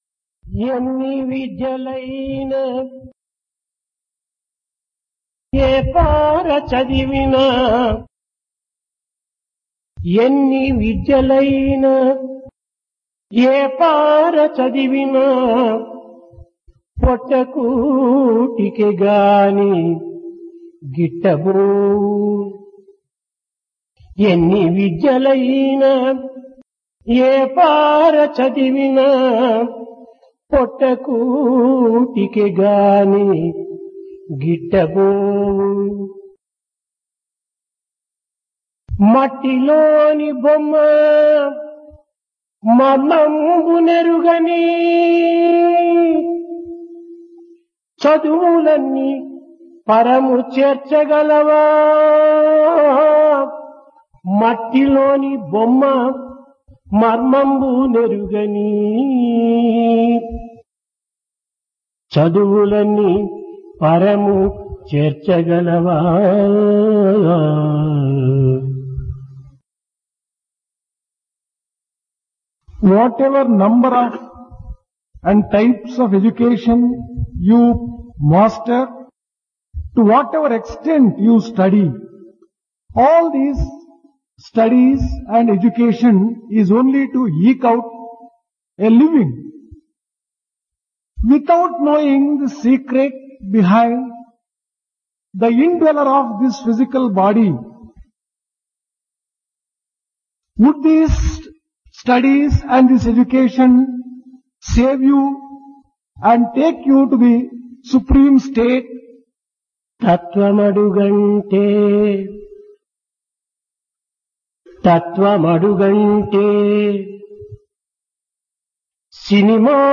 Discourse
Place Prasanthi Nilayam Occasion Dasara